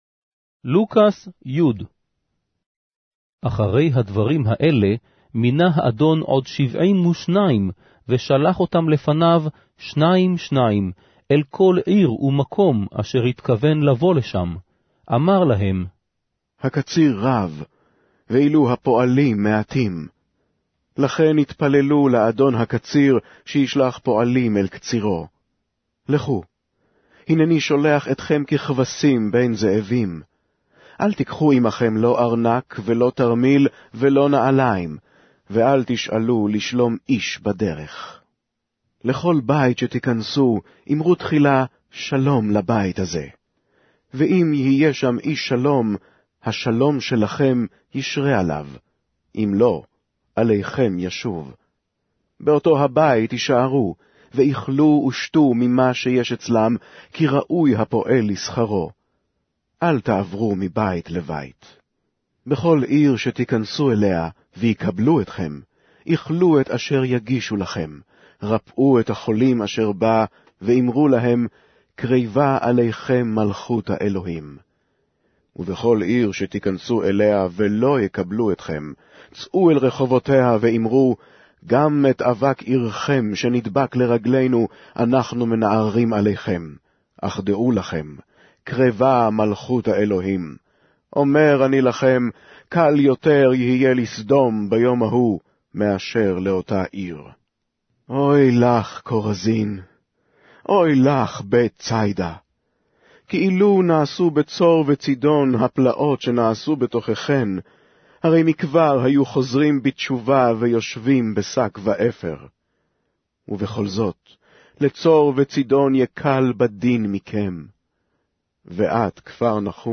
Hebrew Audio Bible - Luke 12 in Bnv bible version